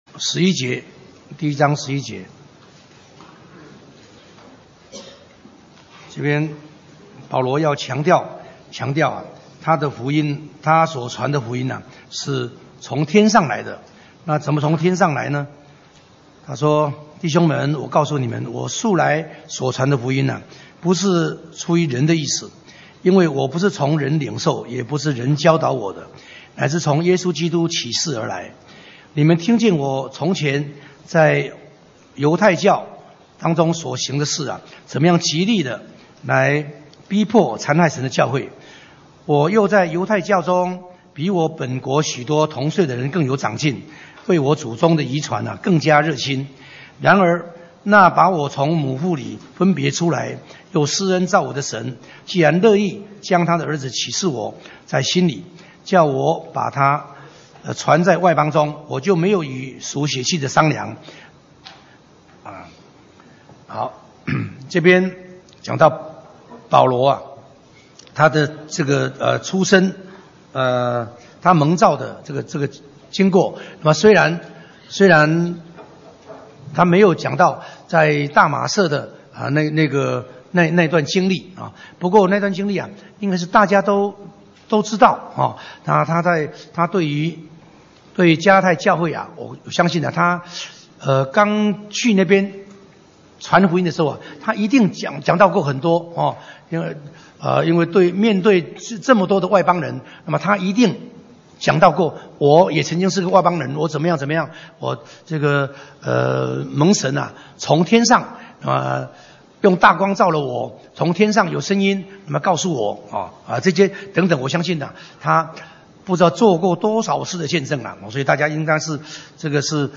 講習會
地點 台灣總會 日期 02/15/2017 檔案下載 列印本頁 分享好友 意見反應 Series more » • 加拉太書 15-1 • 加拉太書 15-2 • 加拉太書 15-3 …